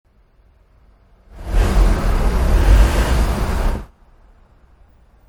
64 pt 5 enhanced traffic noise.mp3